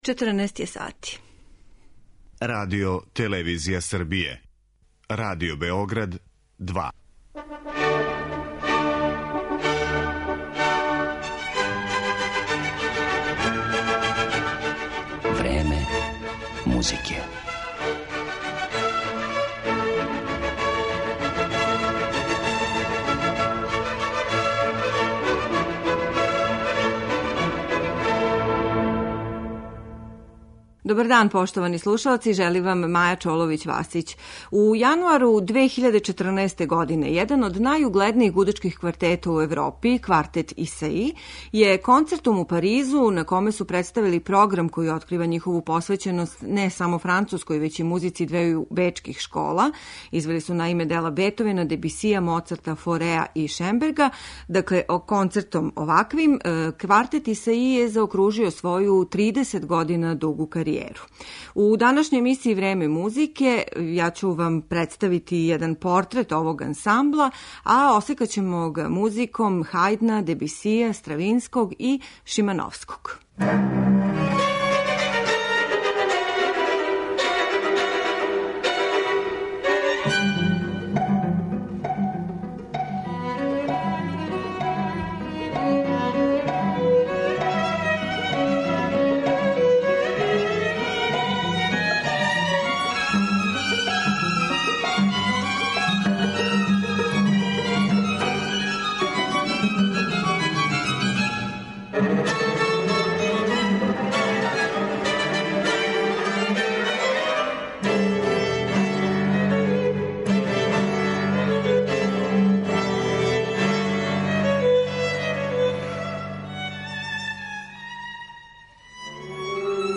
У јануару 2014 године, угледни француски гудачки квартет Исаи (назван тако по славном белгијском виолинисти и композитору Ежену Исаију) престао је да постоји.